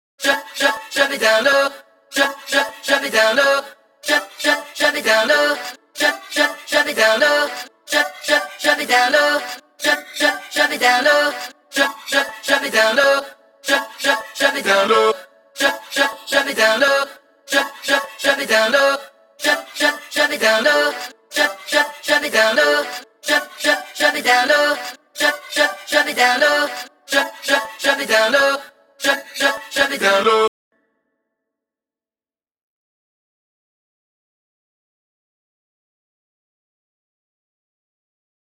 vox125bpm.ogg